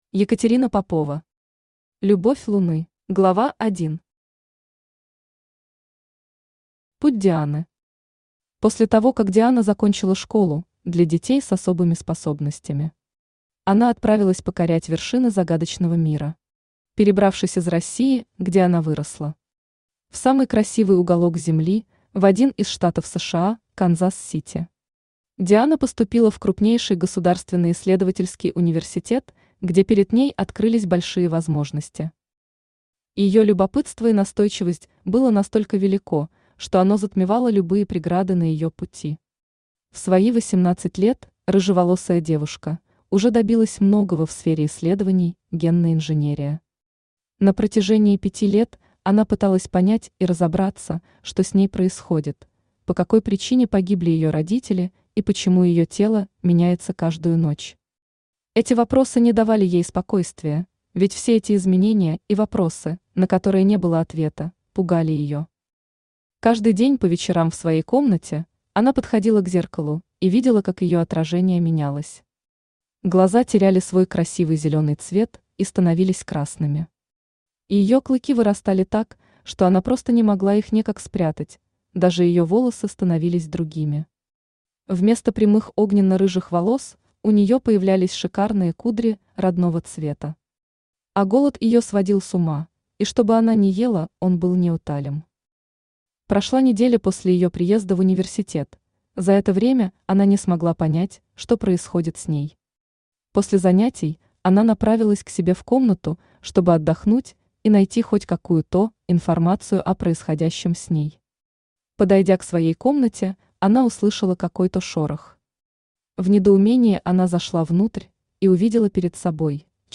Aудиокнига Любовь луны Автор Екатерина Попова Читает аудиокнигу Авточтец ЛитРес.